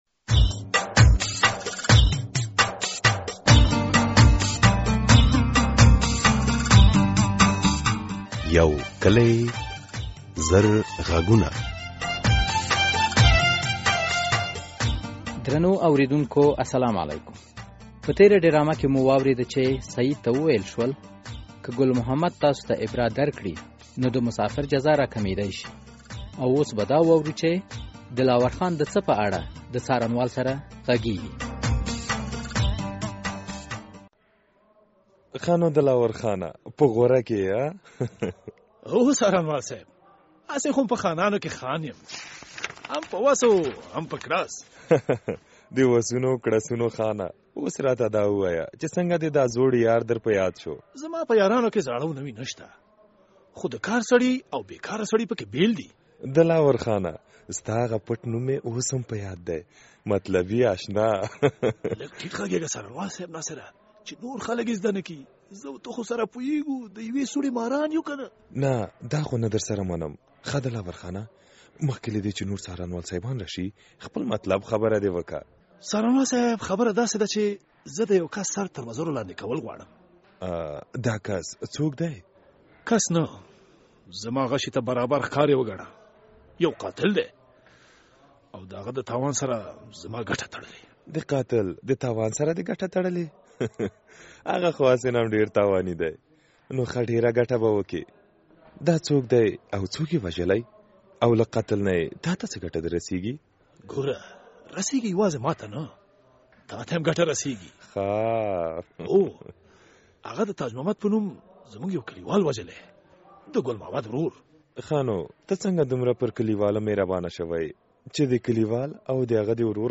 یو کلي او زر غږونه ډرامه هره اوونۍ د دوشنبې په ورځ څلور نیمې بجې له ازادي راډیو خپریږي.